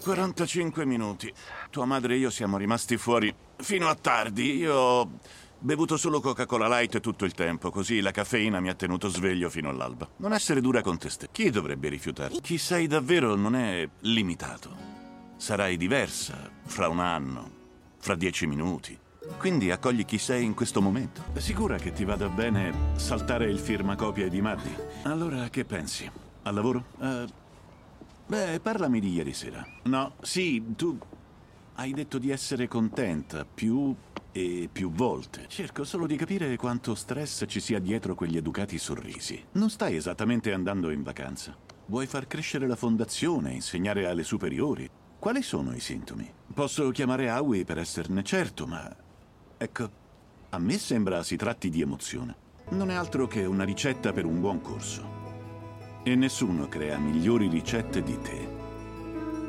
Il mondo dei doppiatori